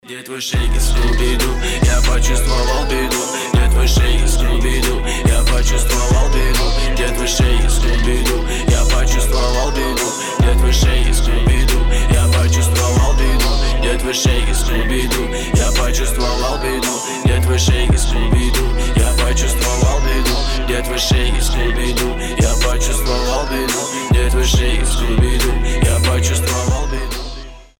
• Качество: 320, Stereo
русский рэп
мощные басы
качающие